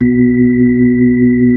Index of /90_sSampleCDs/Roland LCDP10 Keys of the 60s and 70s 2/B-3_Brite Fast L/B-3_Brite Fast L